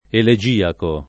[ ele J& ako ]